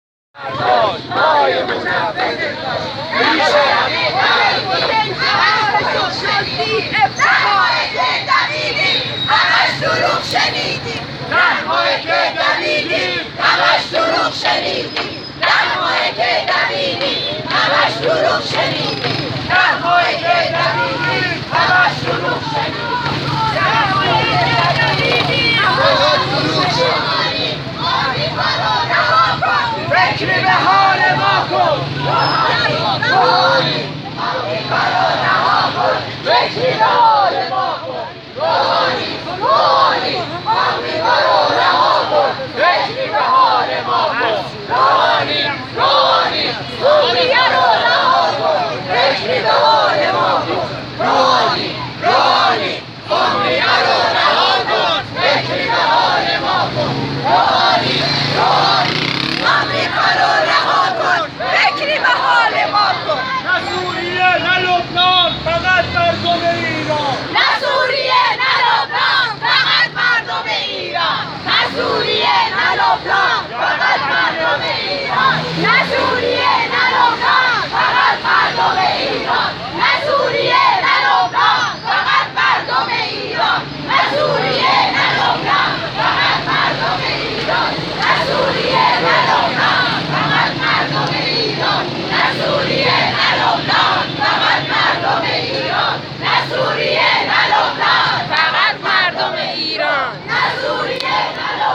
تظاهرات غارت‌شدگان در تهران با شعار:‌ سوریه رو رها کن فکری به‌حال ما کن + فیلم – ایرانیان کانادا- تندرکانادا